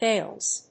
発音記号・読み方
/helz(米国英語), heɪlz(英国英語)/